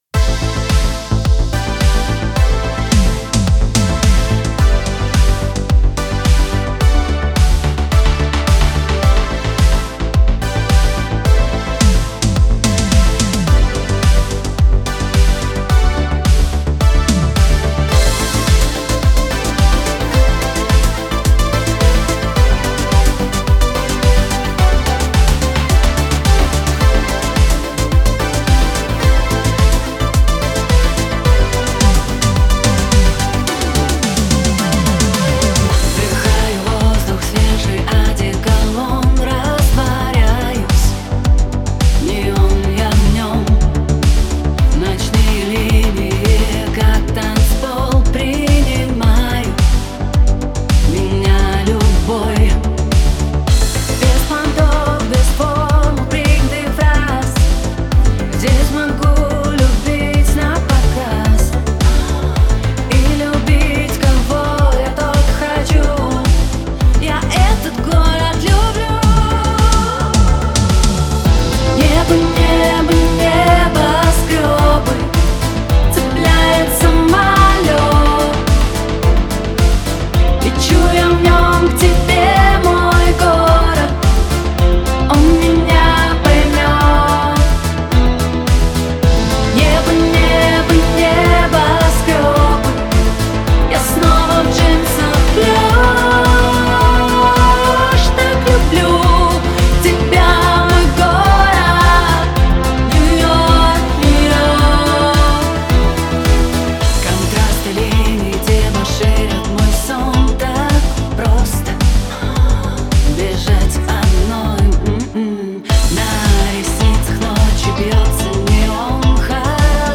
Такси-такси :) синт-поп